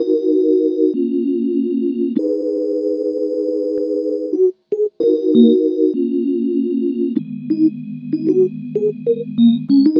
Night Rider - Organ.wav